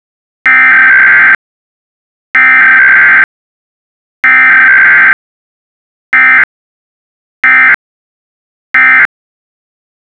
eas-same-encoder - A Python script that generates valid EAS SAME messages.